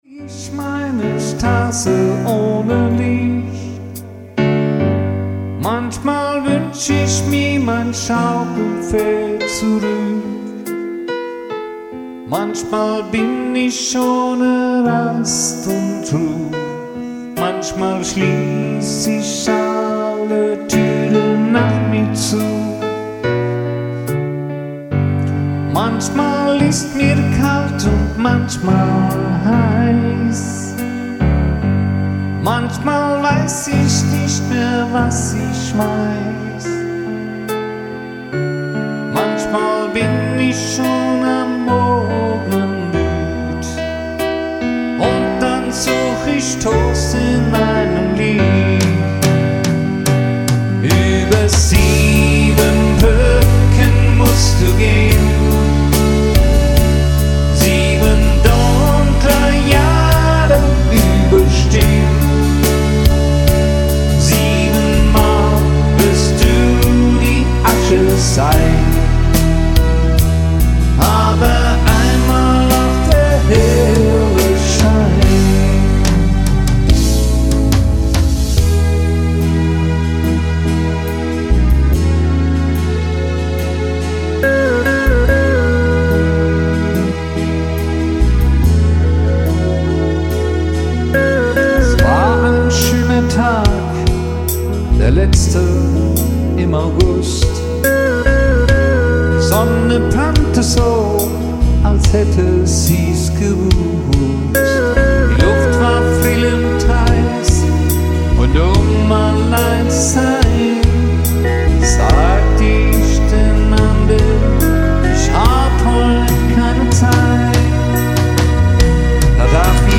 Alleinunterhalter mit Keyboard & Gesang
KEYBOARD & GESANG